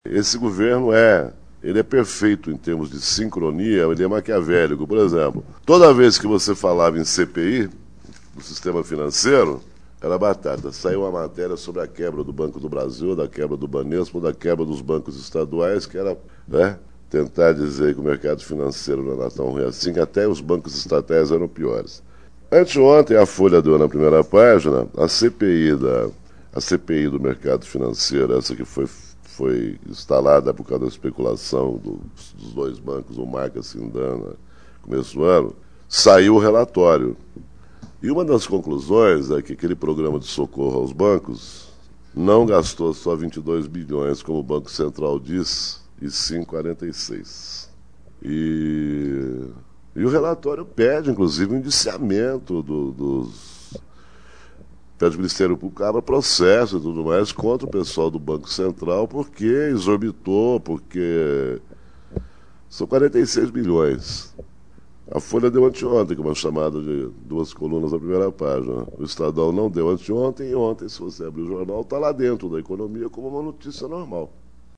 Também falou do rombo das privatizações, os dilemas das esquerdas e o posicionamento político dos jornais na era FHC. Abaixo, ouça trecho do áudio da palestra.